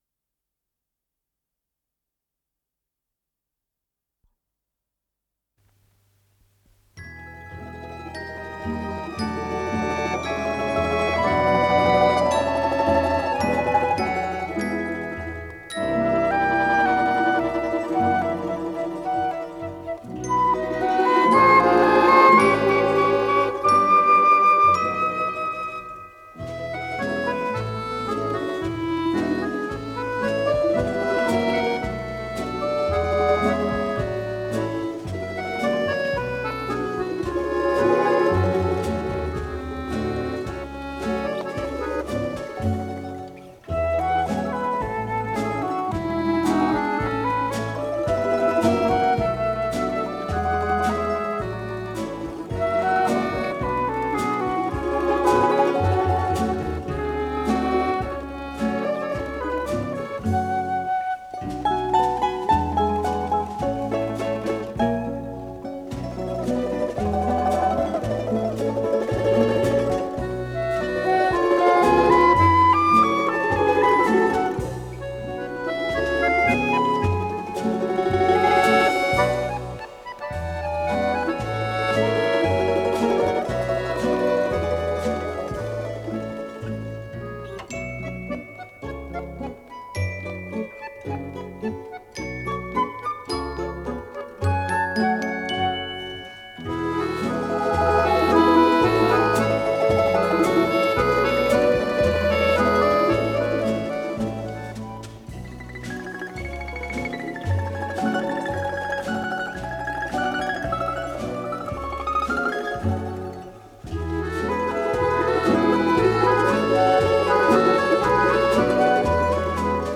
с профессиональной магнитной ленты
ВариантДубль моно
Пьеса для инструментального ансамбля